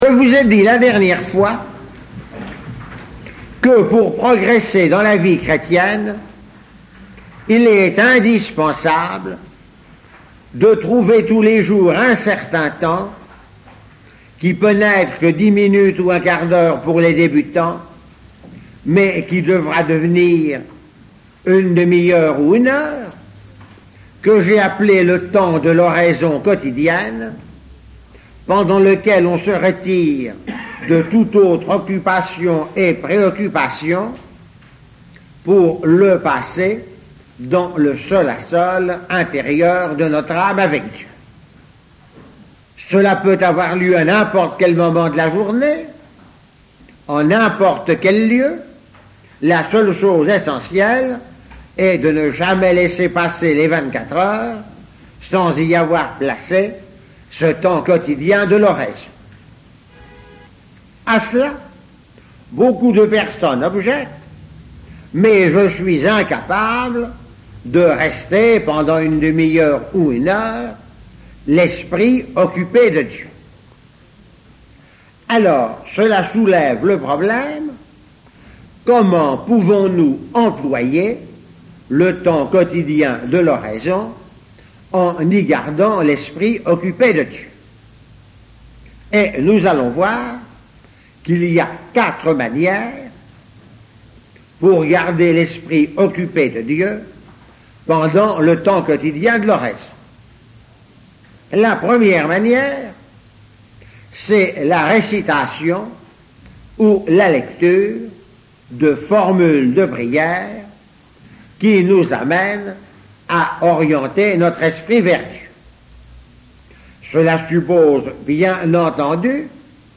Enseignement
Il s'agit de documents d'archive dont la qualité technique est très médiocre, mais dont le contenu est particulièrement intéressant et tout à fait conforme à l'enseignement de l'Eglise Catholique.